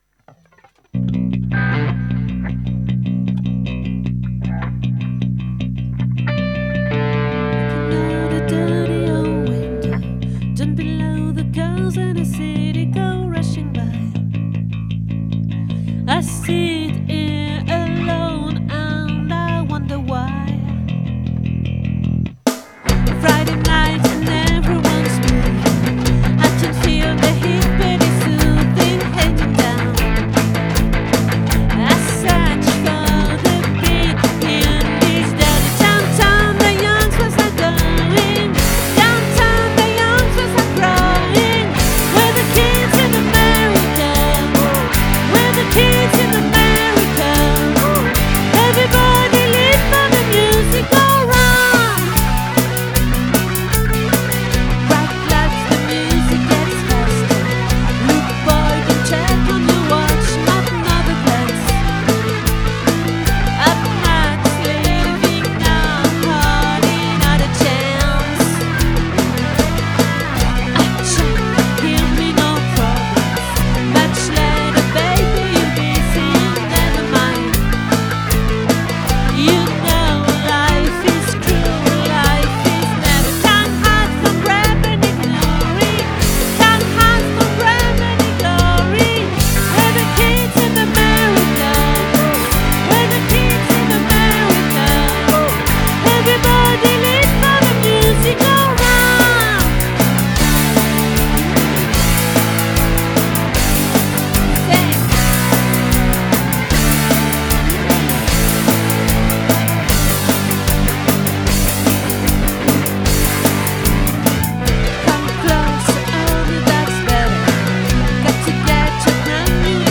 🏠 Accueil Repetitions Records_2025_03_03